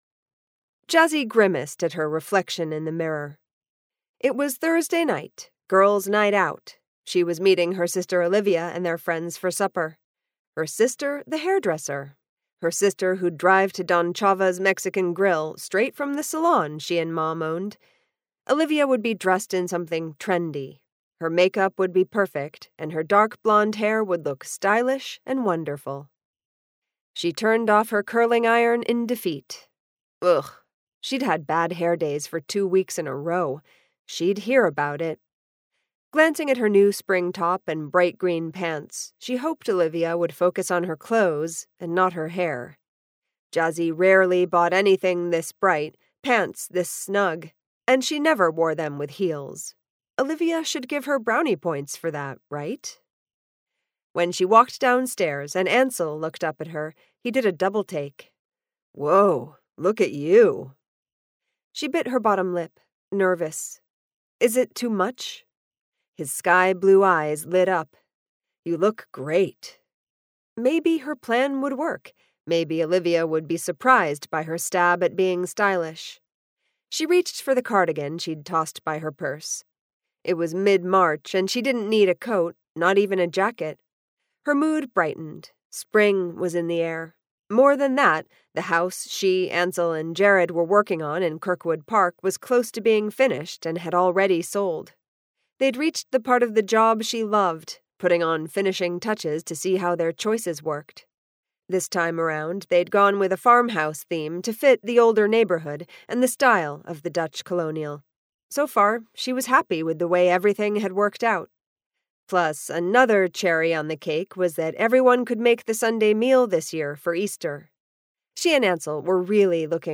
Body In the Beauty Parlor - A Jazzi Zanders Mystery, Book Six - Vibrance Press Audiobooks - Vibrance Press Audiobooks